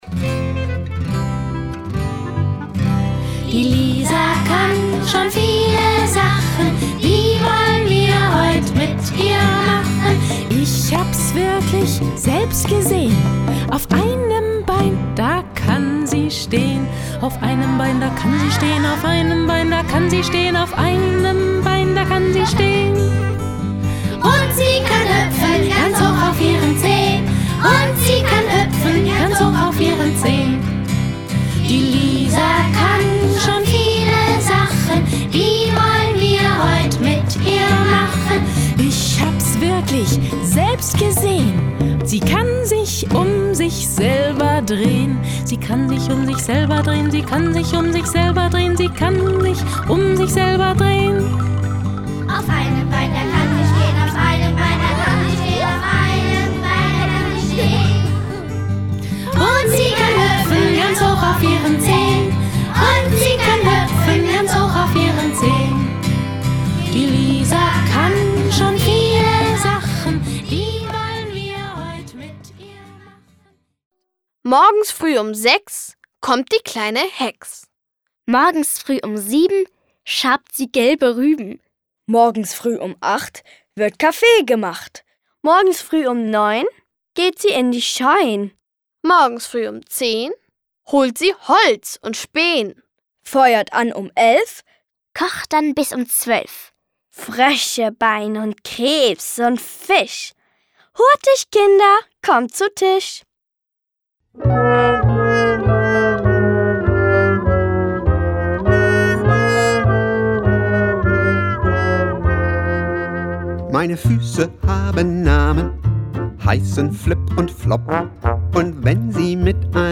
Lieder, Reime und Geschichten